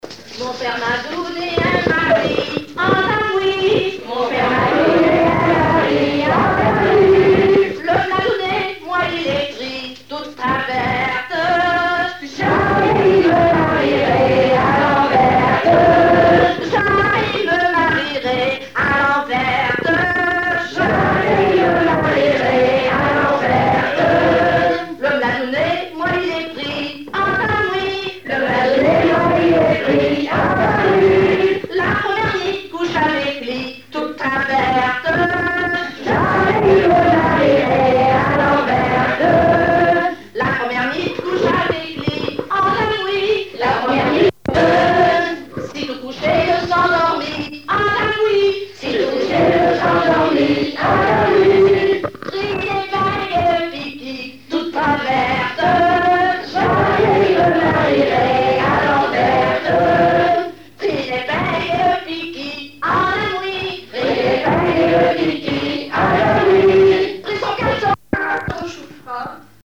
Patois local
danse : ronde : rond de l'Île d'Yeu
Pièce musicale inédite